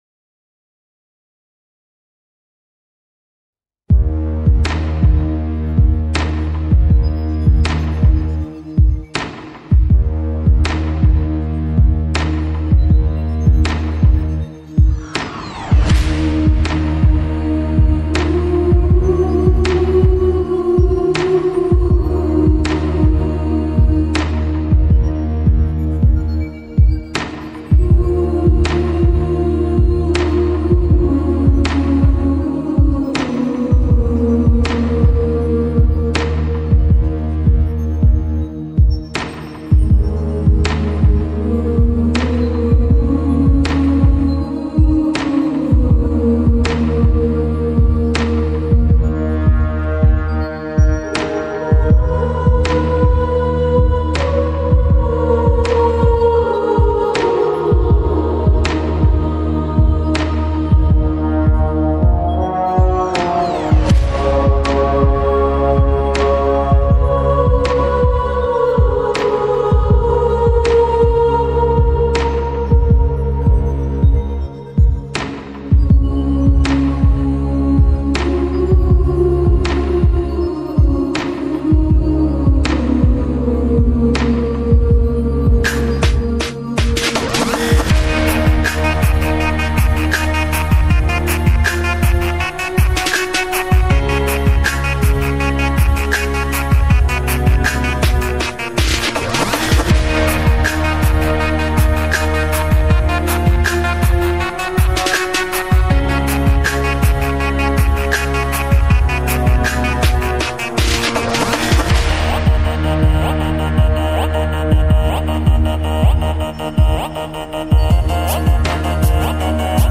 Bezumno_krasivaya_muzyka_pronikaet_v_dushu_Poslushaqte_Bespodobnaya_atmosfera_MP3_128K.mp3